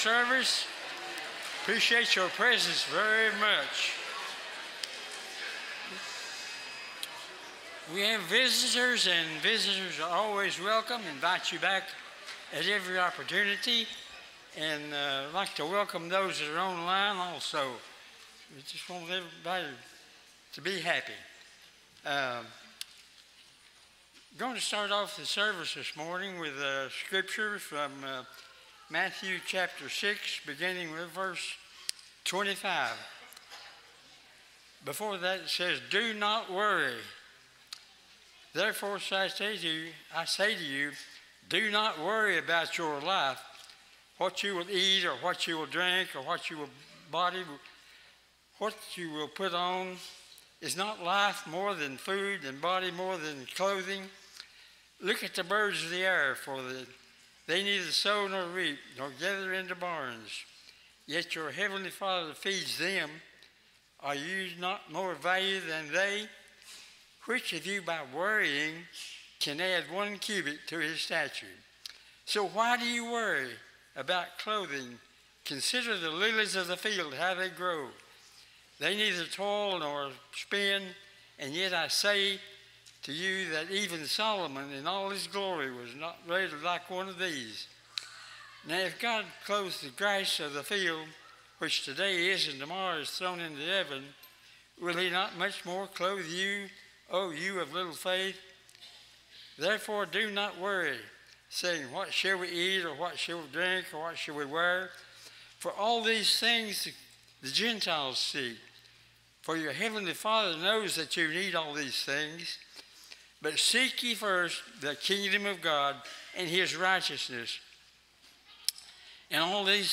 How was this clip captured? Acts 2:36, English Standard Version Series: Sunday AM Service